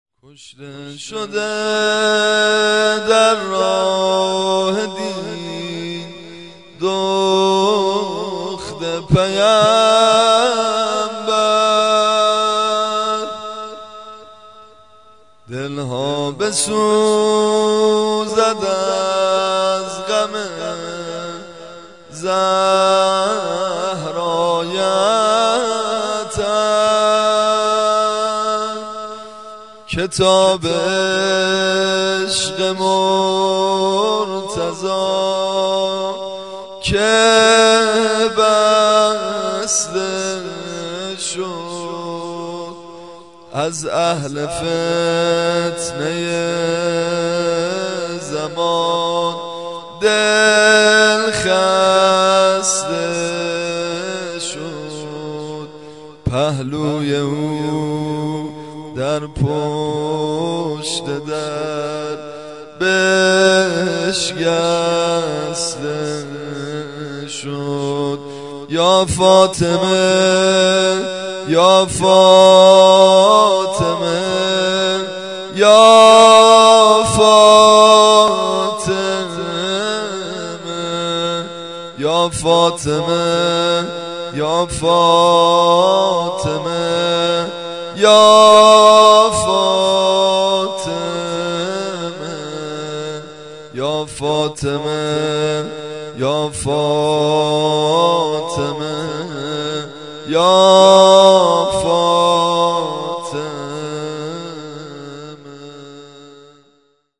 نوحه زمزمه براي شهادت حضرت زهرا (س) ( کشته شده در راه دین دخت پیمبر)
شاعر : امير عباسي